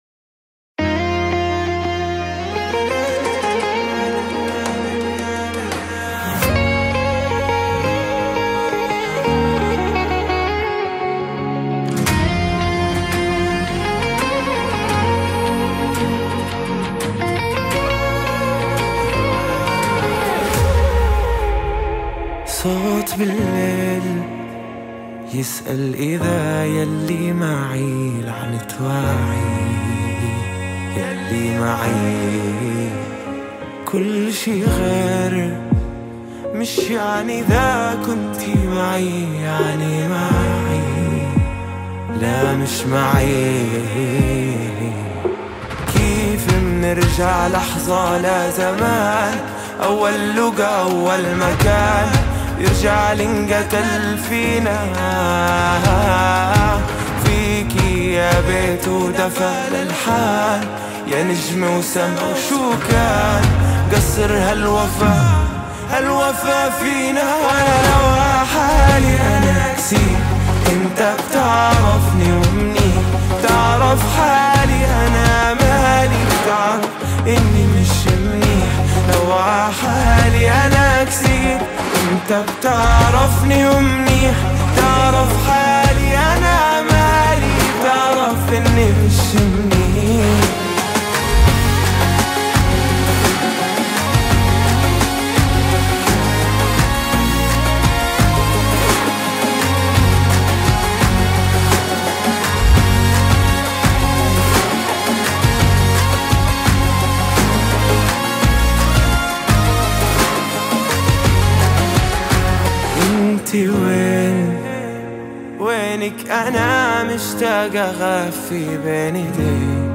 النوع الموسيقي بوب بديل (Alternative Pop)